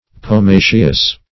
Pomaceous \Po*ma"ceous\, a. [LL. ponum an apple.]